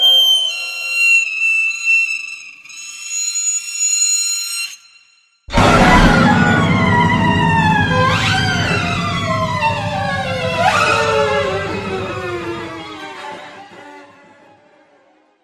Download Scary Long sound effect for free.
Scary Long